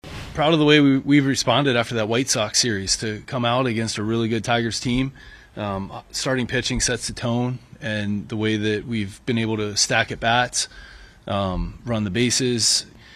Manager Don Kelly says the Pirates have replaced last weekend’s poor performance with some excellent work the last two nights.